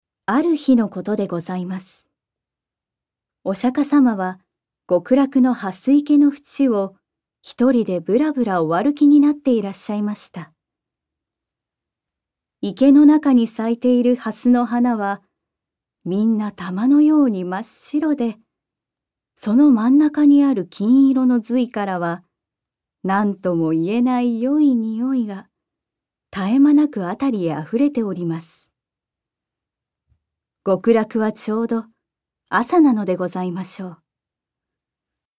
ナレーション3